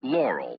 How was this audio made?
Audio S2. A lowpass filtered version, usually biased toward Laurel. ly_figure_lowpass.wav